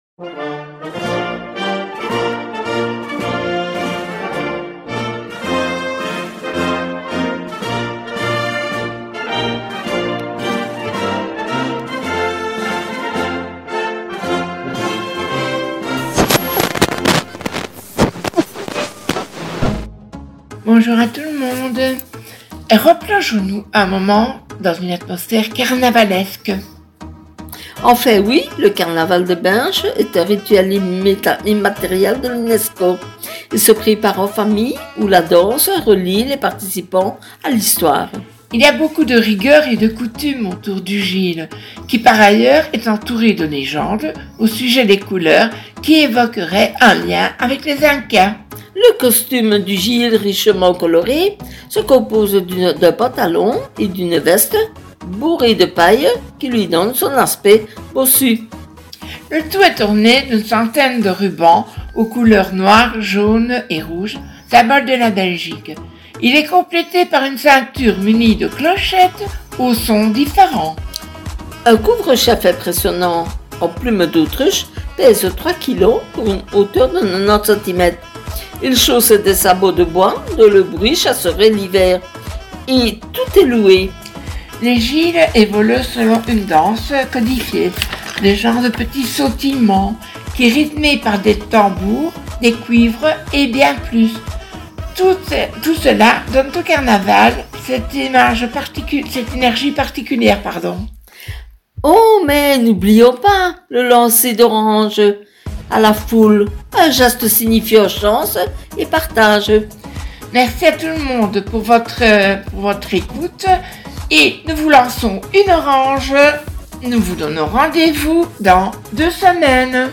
Ambiance Festive pour cette Nouvelle Minute Belge